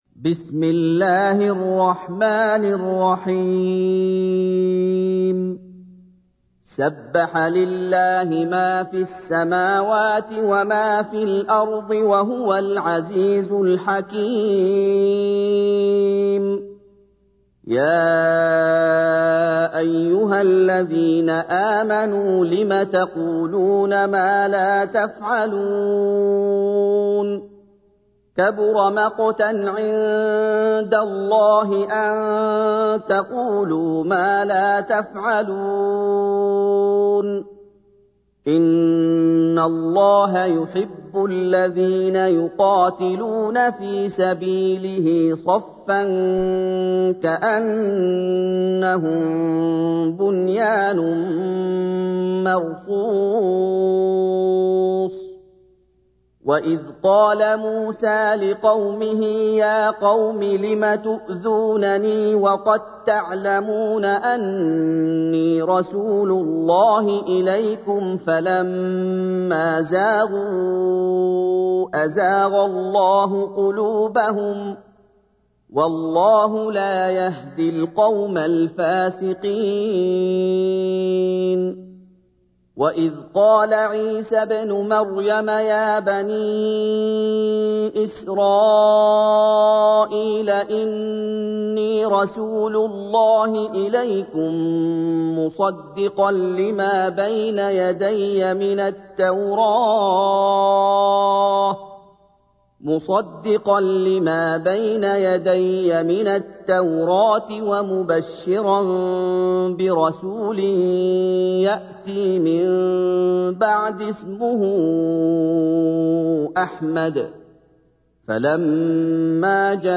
تحميل : 61. سورة الصف / القارئ محمد أيوب / القرآن الكريم / موقع يا حسين